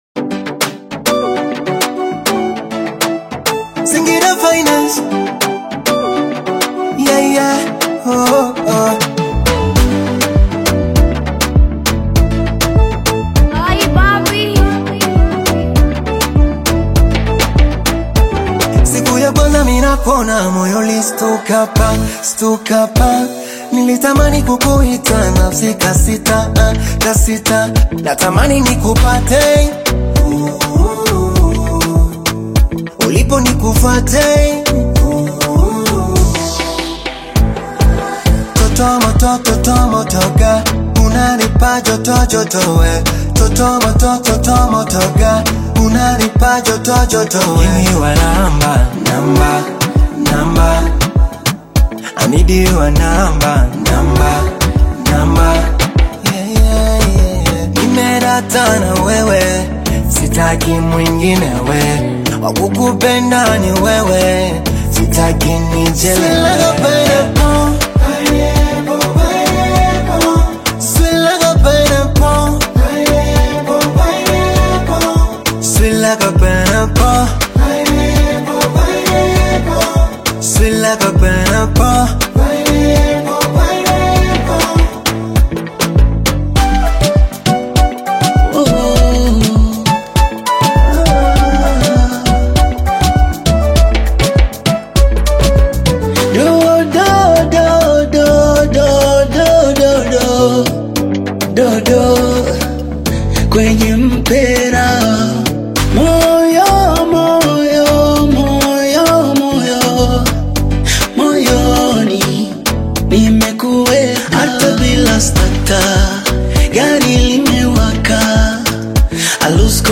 is a vibrant Afro-Pop/Bongo Flava single